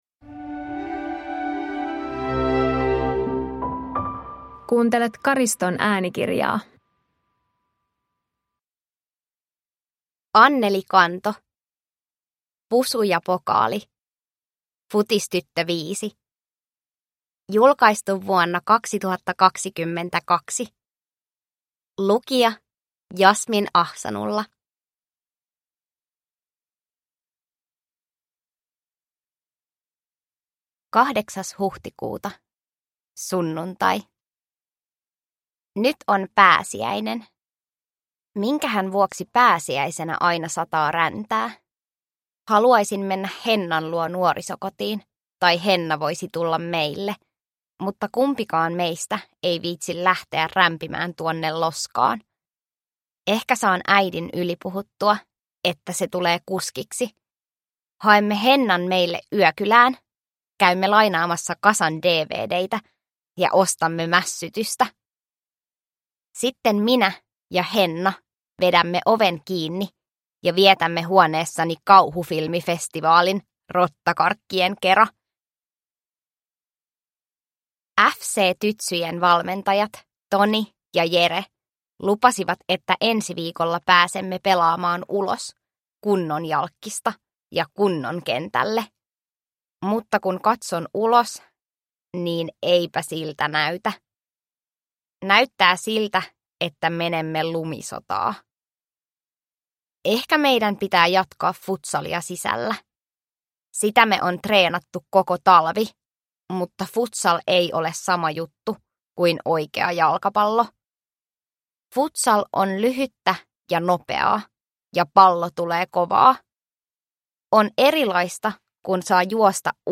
Pusu ja pokaali – Ljudbok – Laddas ner